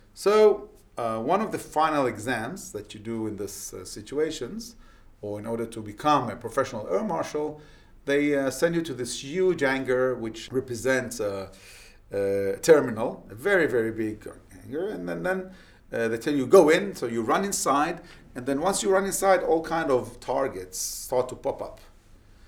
Dans ce court exemple, vous entendrez un court discours de témoignage qui a été enregistré dans une zone avec des bruits de fond.
Le niveau d'enregistrement dans ce cas était un peu élevé et il y a une distorsion notable dans certaines zones.
Sample-2_Before-Restoration.wav